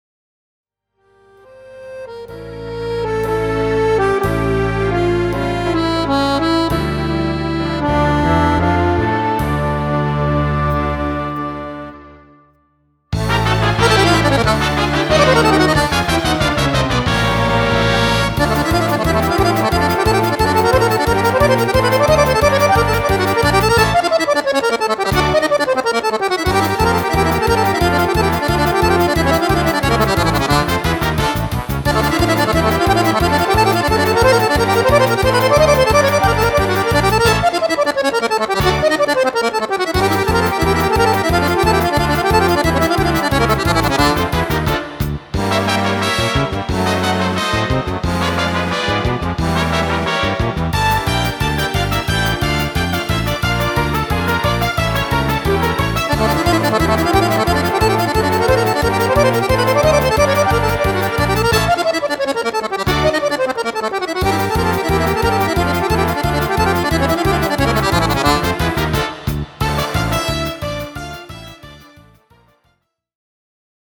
Czardas
Fisarmonica
Strumento Fisarmonica (e Orchestra)